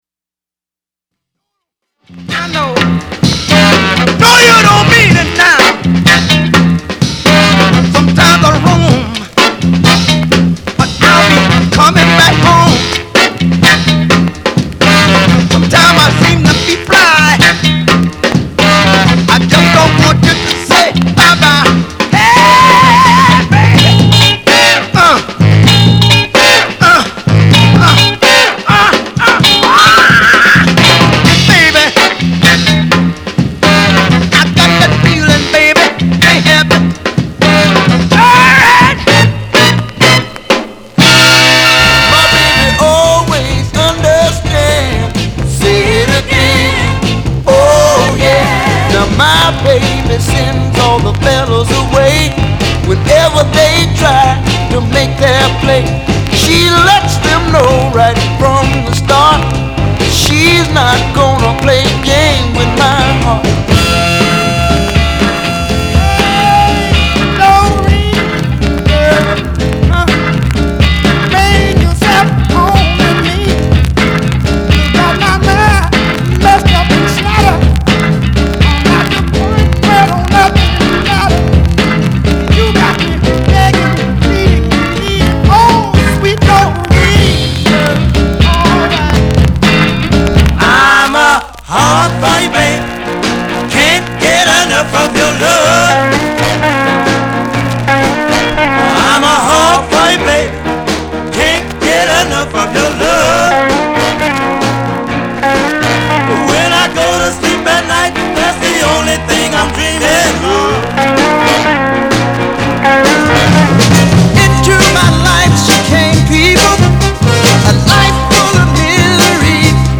R&B、ソウル
/盤質/両面 やや傷あり/全体的に細かい傷あり/US PRESS